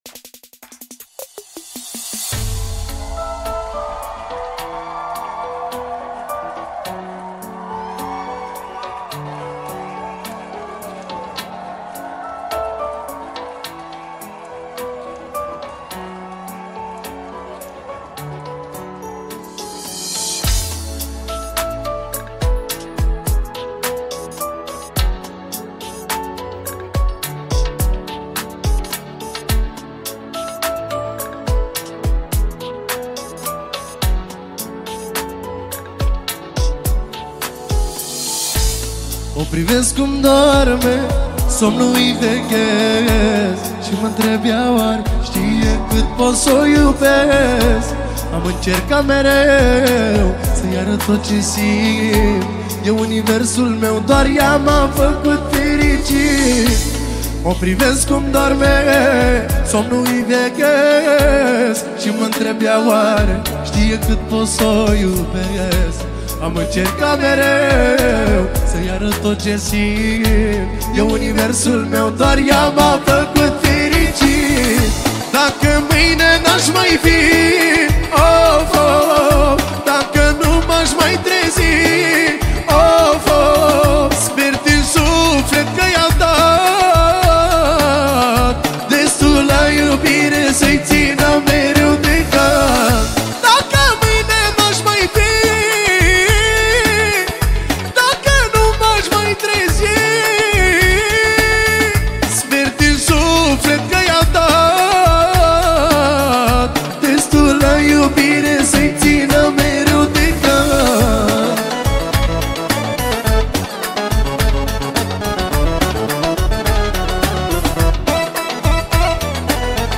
Live
Data: 18.10.2024  Manele New-Live Hits: 0